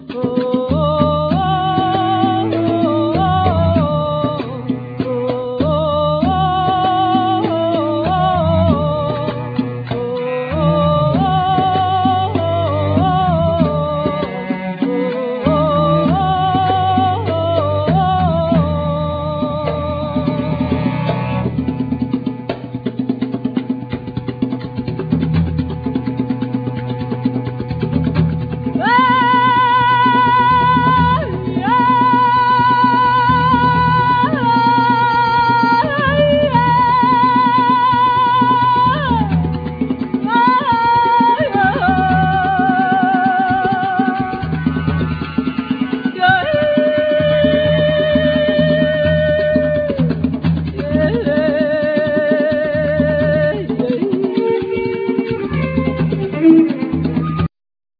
Voice, Maracas
Tambora, Llamador, Redoblante
Alegre, Platillos, Guache
Accoustic bass
Cello
Trombone